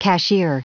Prononciation du mot cashier en anglais (fichier audio)
cashier.wav